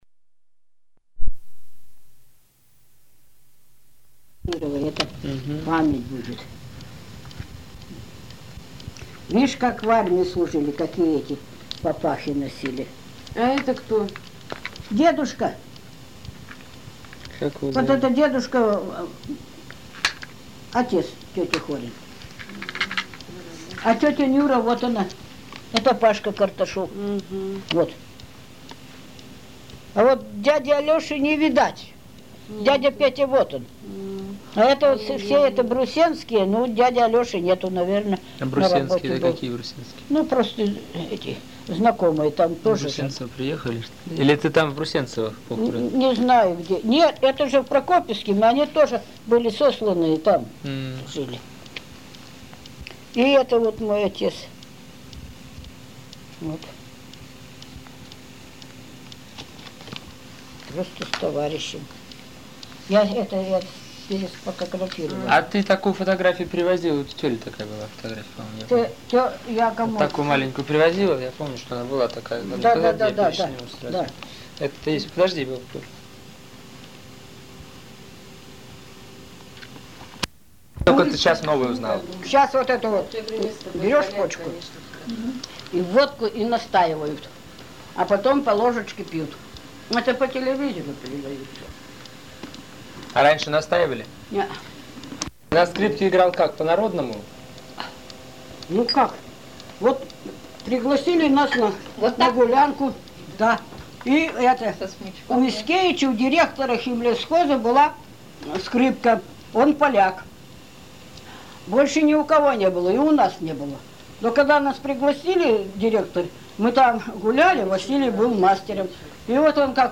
Он предоставил нам записи интервью с людьми, пережившими трагедию раскулачивания. Мы предлагаем вашему вниманию его полевые материалы. Несмотря на то, что время от времени они отрывочны, и качество записи невысокое, информация, которую содержат рассказы респондентов, представляет интерес для исследователей истории России ХХ века, народной культуры.